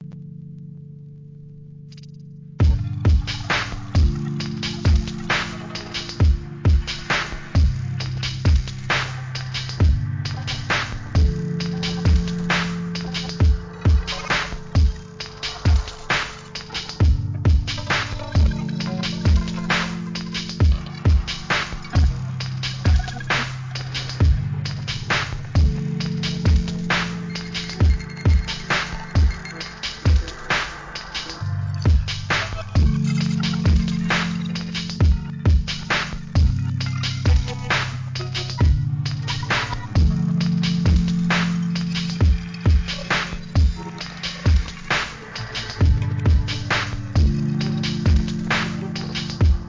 DOWNBEAT ブレイクビーツ